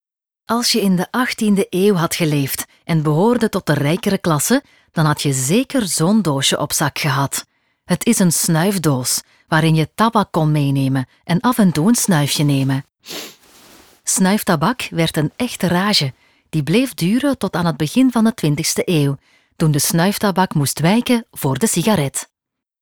Vertrouwd, Vriendelijk, Natuurlijk
E-learning